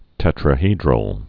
(tĕtrə-hēdrəl)